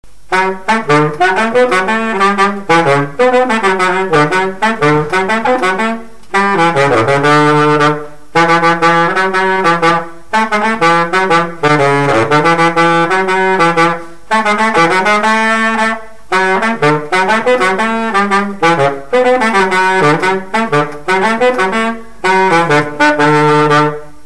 instrumental
danse
Pièce musicale inédite